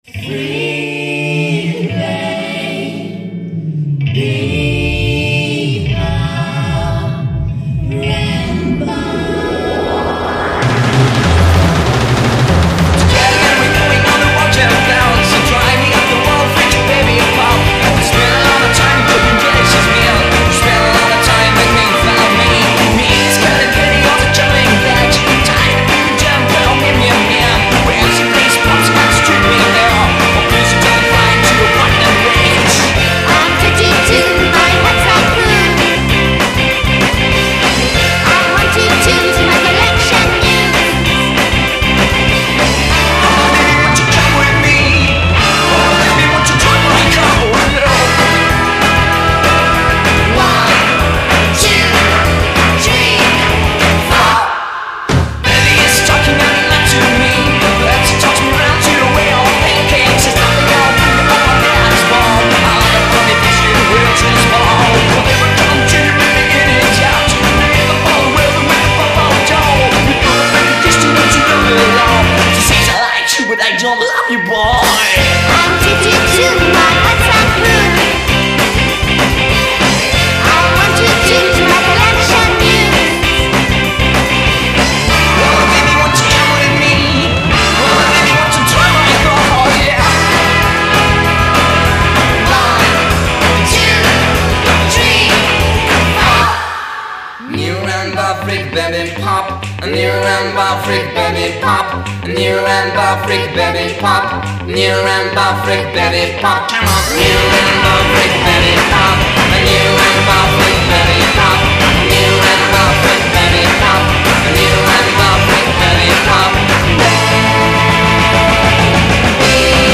Rock Bubble Gun mutant, survolté et plein de féminité